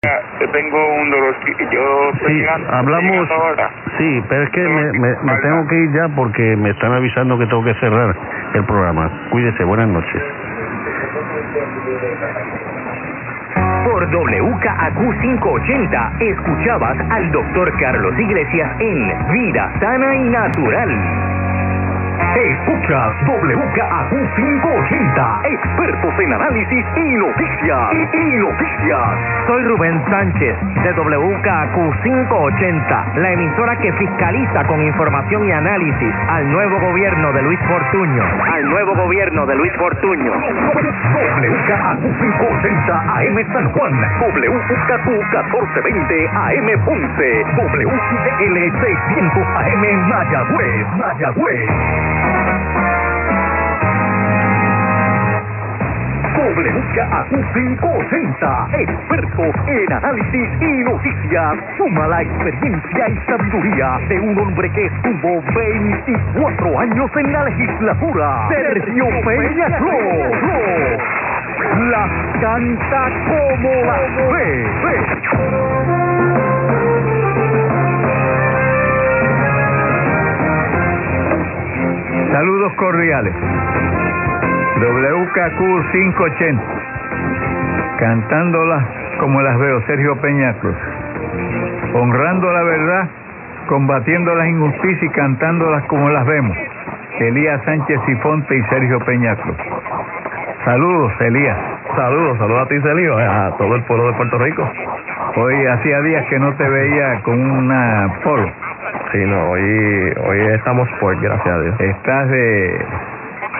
SOME GOOD SIGNALS FROM PUERTO RICO: Yesterday morning I had a cracking signal on 580 from PR at 0500.
CLICK HERE for a recording of WKAQ 580. Superb signal